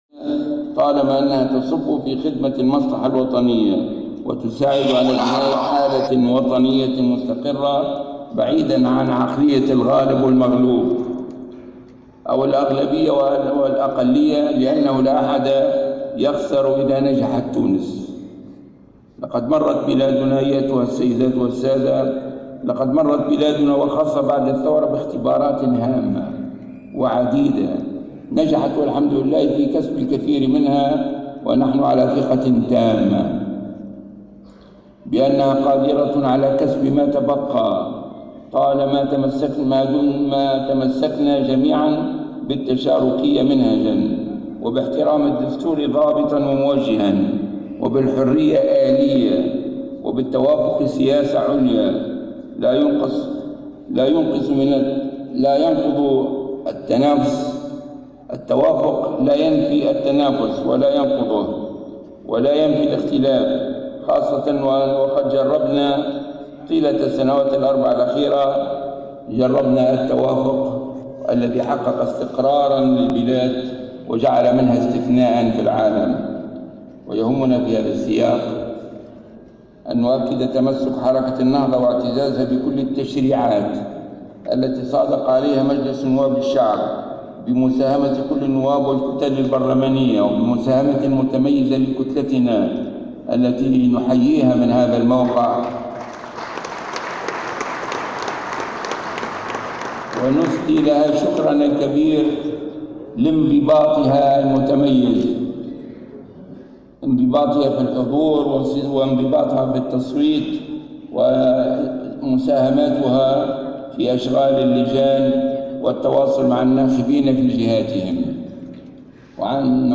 أعلن رئيس حركة النهضة راشد الغنوشي في كلمته التي ألقاها في افتتاح الندوة السنوية الثانية لإطارات الحركة، اليوم السبت، عن مبادرة يدعو فيها البرلمان الى تبني قانون للعفو العام عن مرتكبي الانتهاكات حال الاعتراف وكشف الحقيقة والاعتذار على ان تتولى الدولة جبر ضرر الضحايا وعائلاتهم.